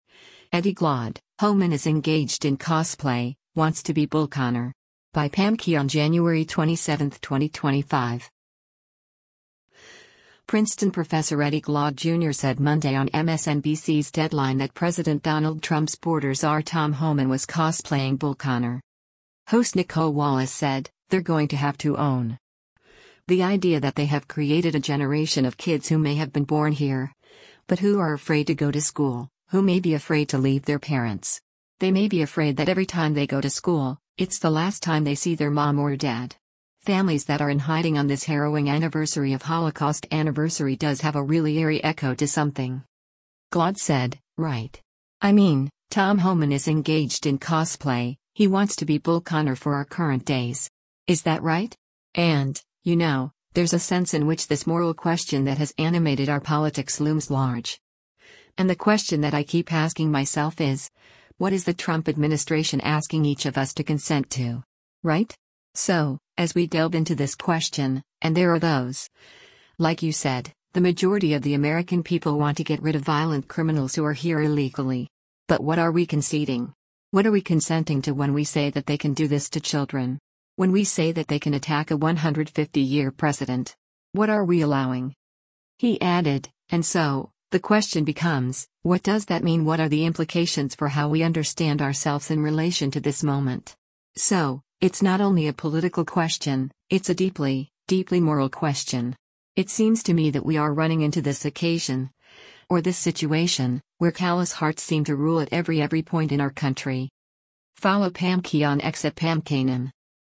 Princeton professor Eddie Glaude Jr. said Monday on MSNBC’s “Deadline” that President Donald Trump’s border czar Tom Homan was cosplaying Bull Connor.